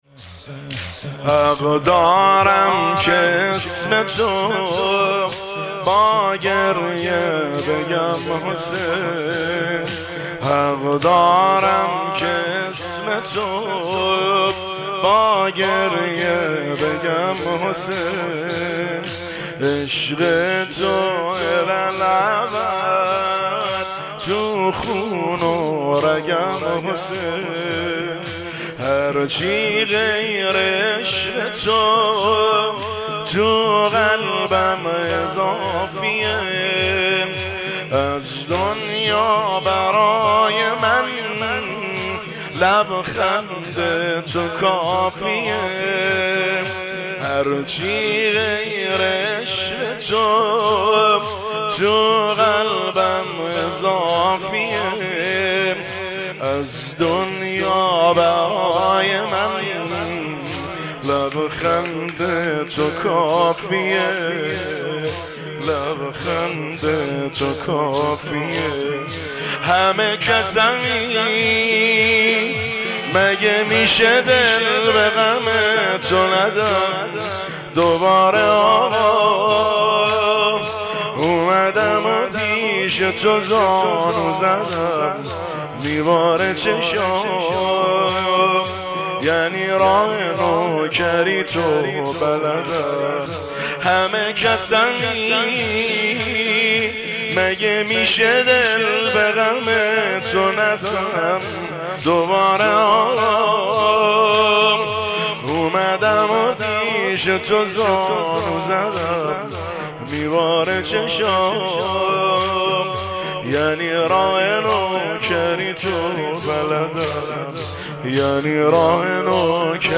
دانلود با کیفیت LIVE